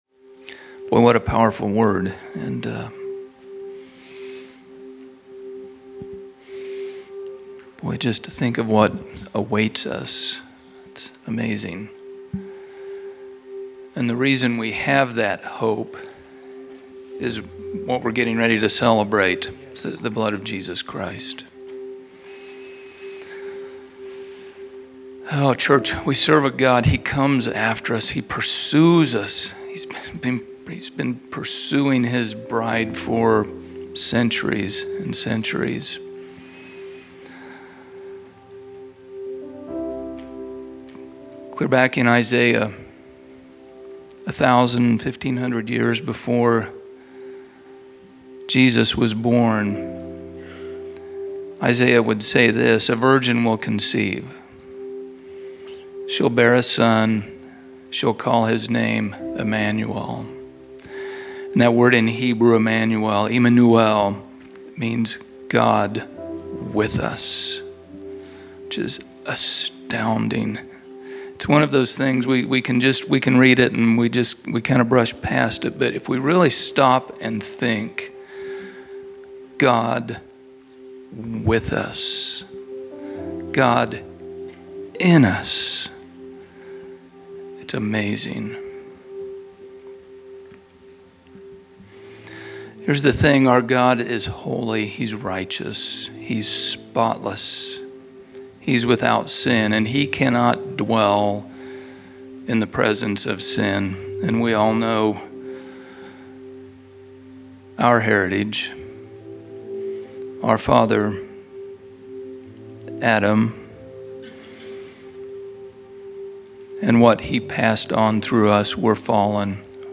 Category: Elder Moment      |      Location: El Dorado
leads us in a moment of prayer and scriptural reflection as we partake in communion together.